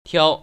[tiāo] 탸오